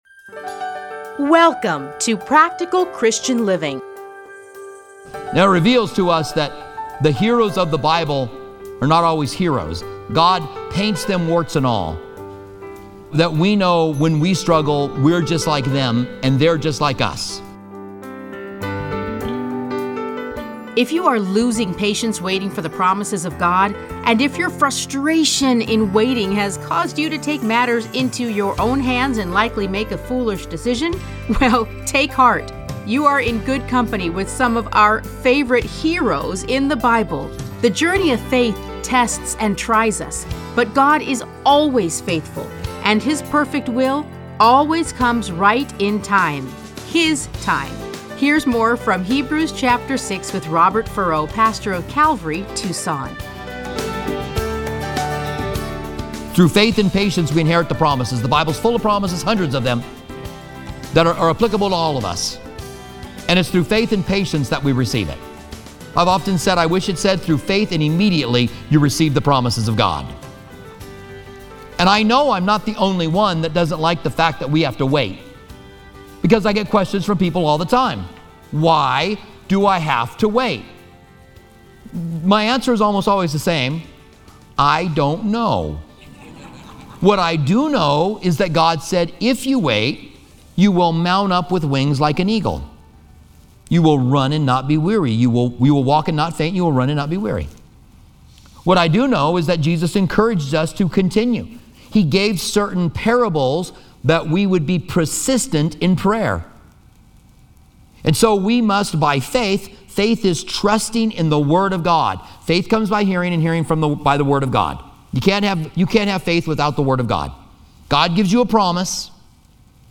Listen to a teaching from Hebrews 6:1-20.